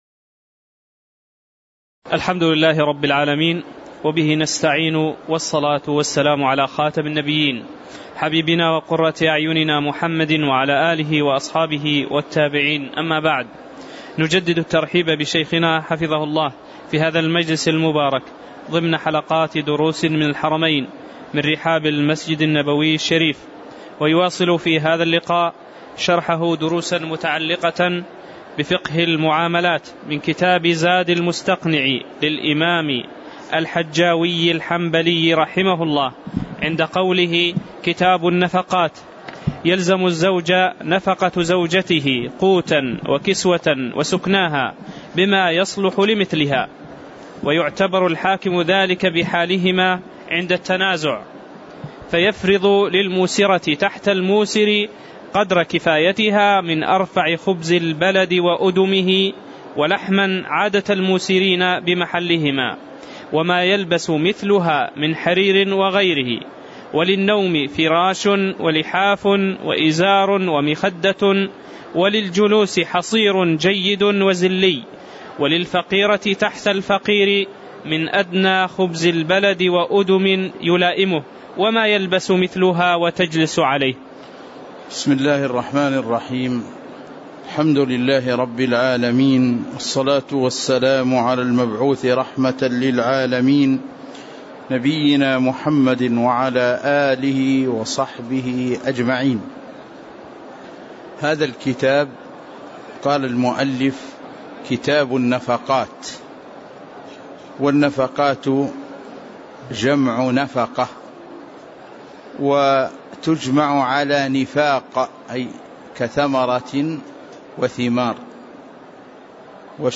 تاريخ النشر ٧ صفر ١٤٣٨ هـ المكان: المسجد النبوي الشيخ